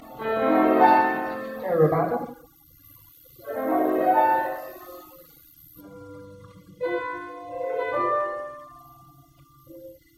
[22]rubato，听着上面的a2，然后接低音的G：